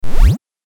シンセ 8bit 敵登場
ヒュイン